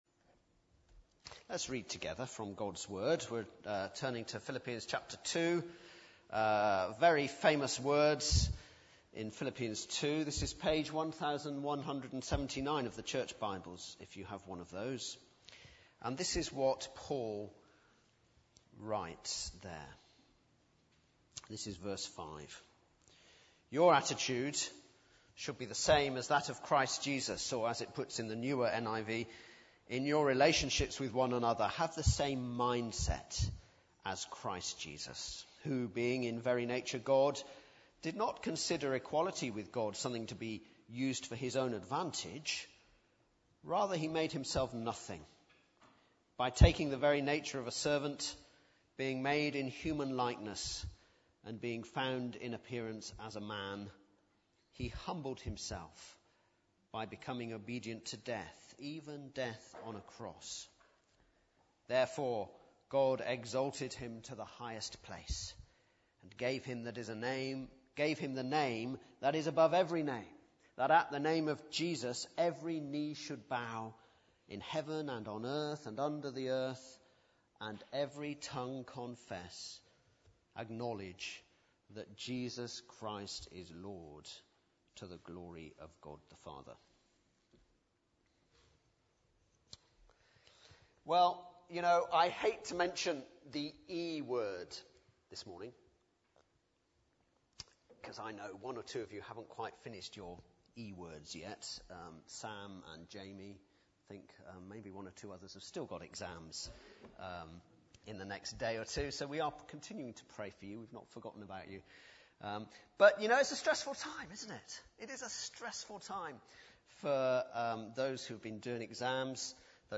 Baptismal Service – June 2015